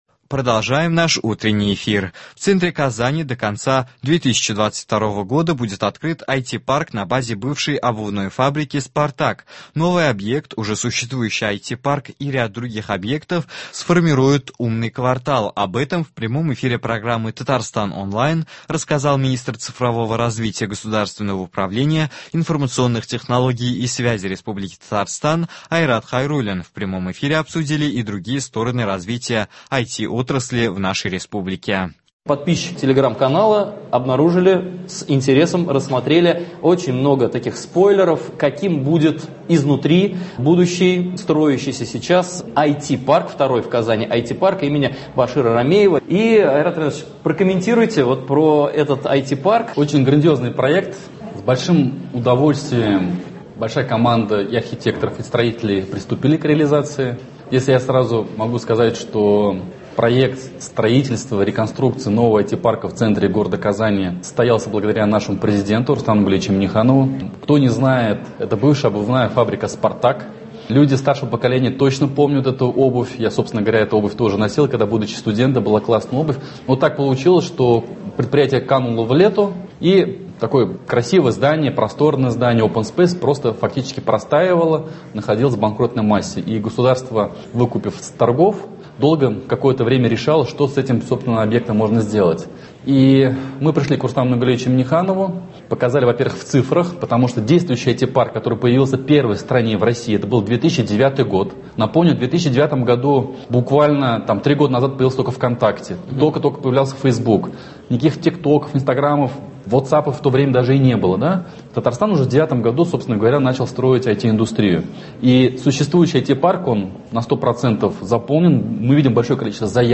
Об этом в прямом эфире программы «Татарстан Онлайн» рассказал министр цифрового развития государственного управления, информационных технологий и связи РТ Айрат Хайруллин.
В прямом эфире обсудили и другие стороны развития ИТ отрасли в республике.